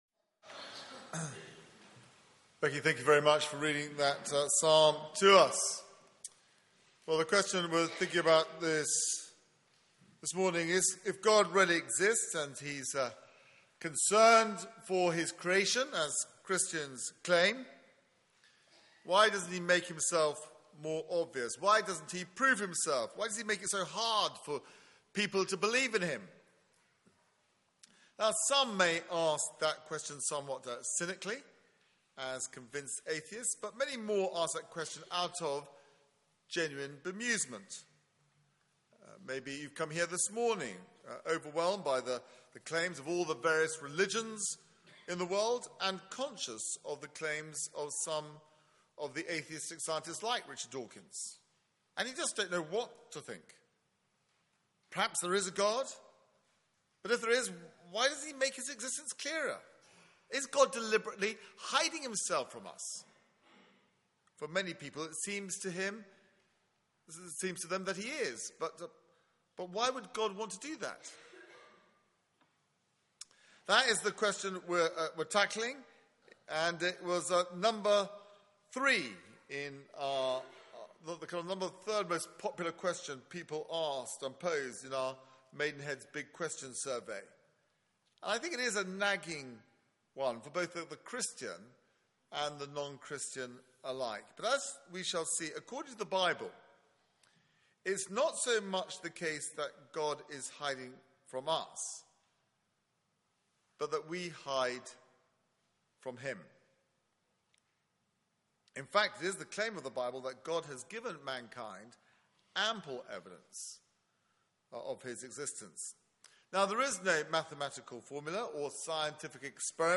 Media for 9:15am Service on Sun 13th Mar 2016 09:15 Speaker
Sermon (11:00) Search the media library There are recordings here going back several years.